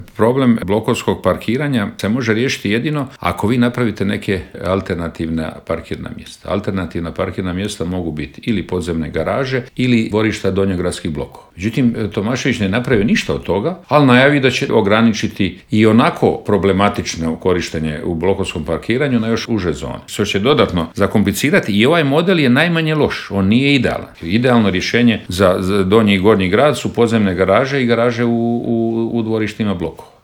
ZAGREB - Predsjednik stranke Plavi Grad, zastupnik u Gradskoj skupštini i kandidat za gradonačelnika Grada Zagreba Ivica Lovrić u Intervjuu Media servisa osvrnuo se na na ključne gradske probleme poput opskrbe plinom, Jakuševca i prometnog kolapsa.